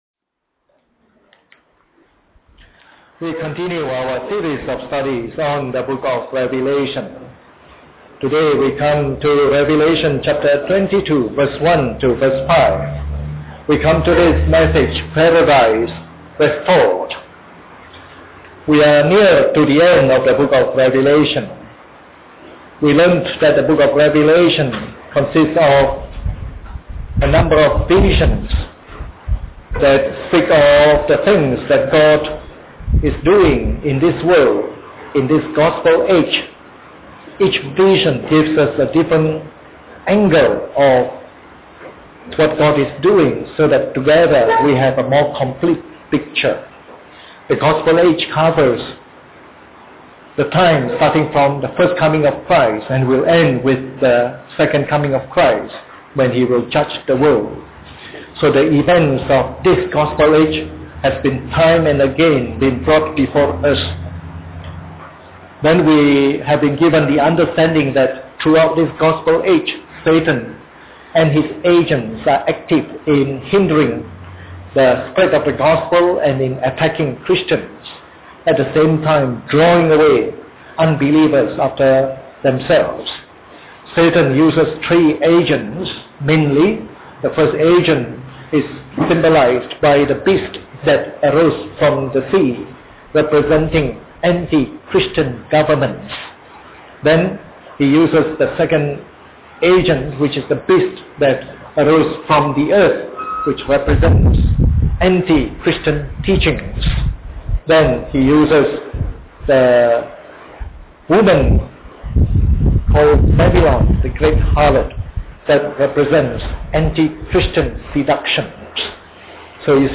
This is part of the morning service series on “Revelation”.